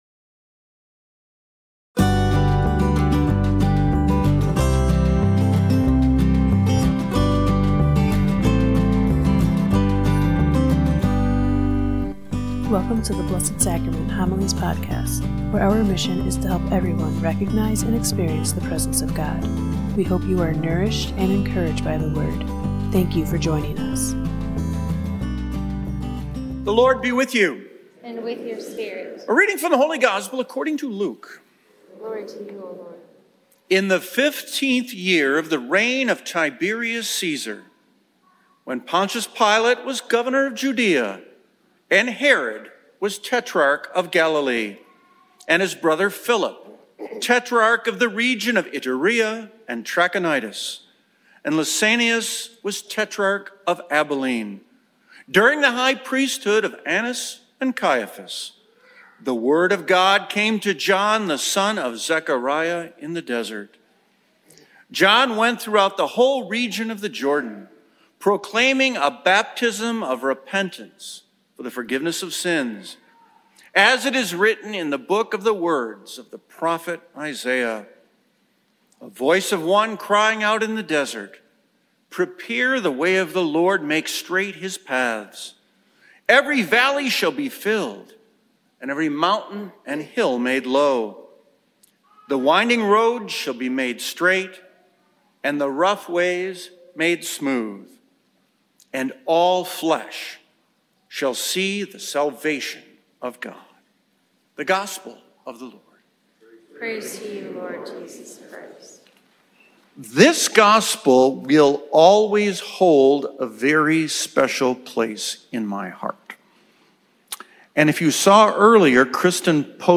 Blessed Sacrament Parish Community Homilies